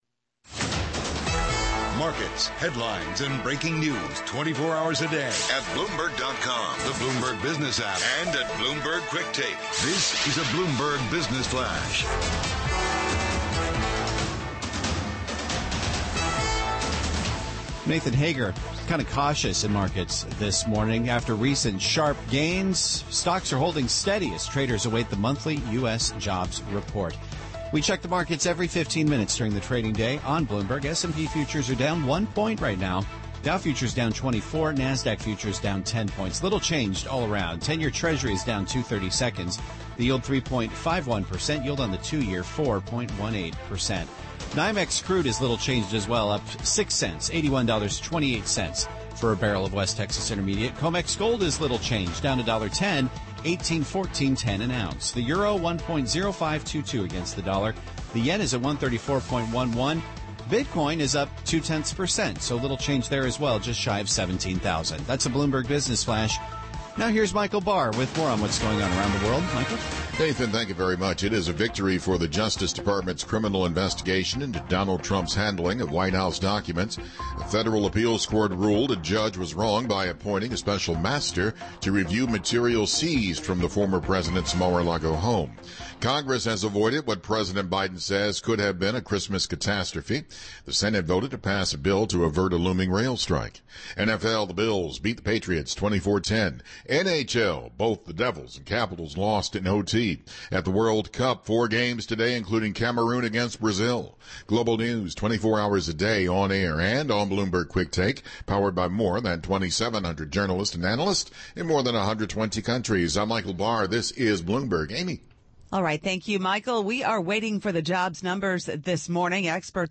INTERVIEW: Axel Lehmann, Chairman, Credit Suisse GUEST